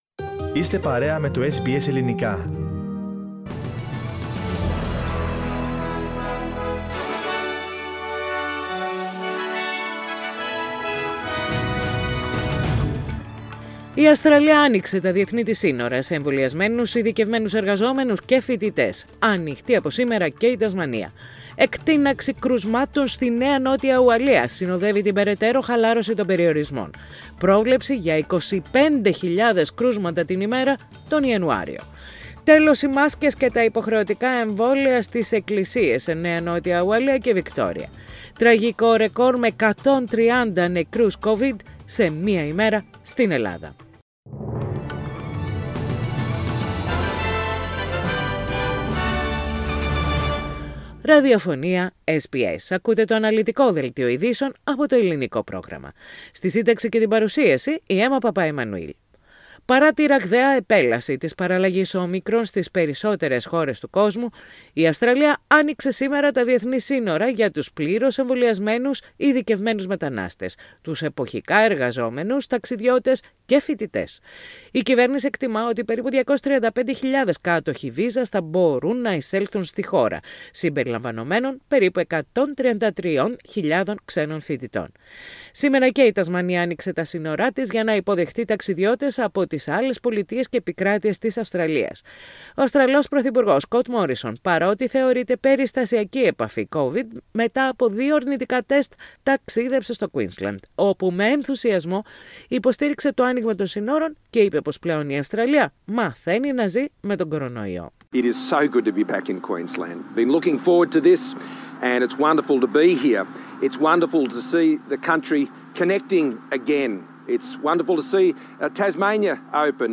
The detailed news bulletin of the day, with the main news from Australia, Greece, Cyprus and the rest of the world.